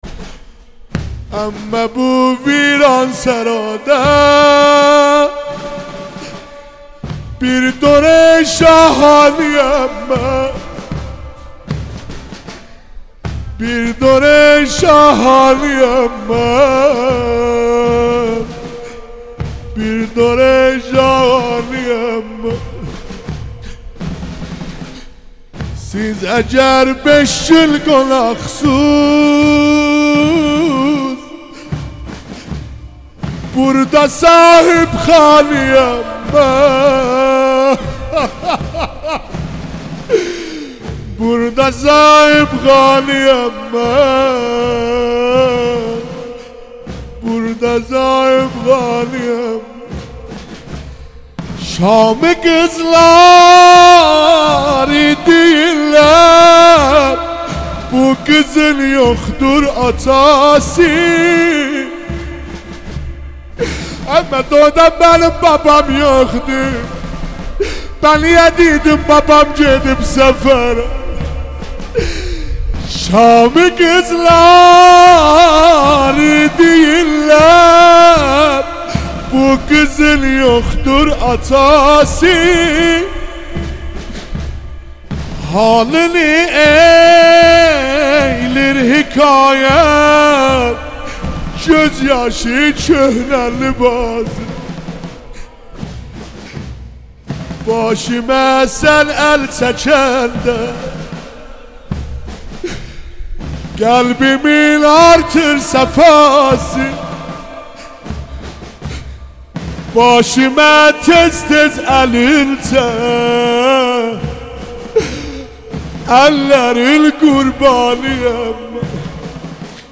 نوحه ترکی